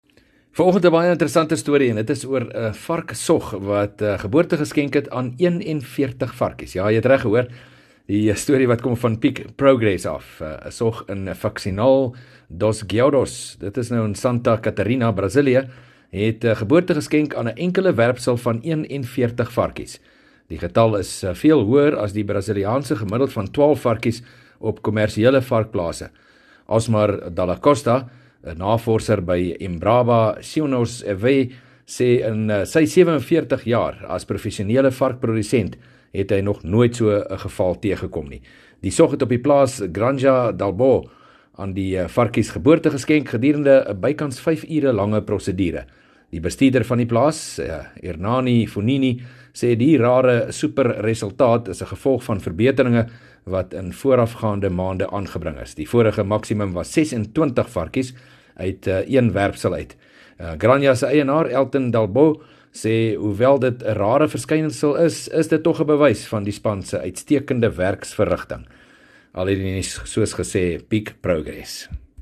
3 Aug PM berig oor ‘n sog in Brasilië wat geboorte geskenk het aan ‘n rekord getal varkies